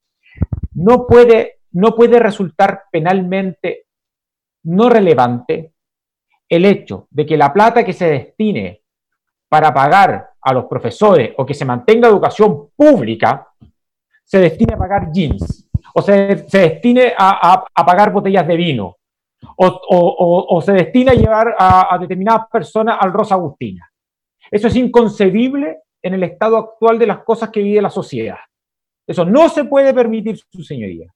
El fiscal jefe de Alta Complejidad Oriente, Felipe Sepúlveda, cuestionó que los dineros se destinen para pagar botellas de vino.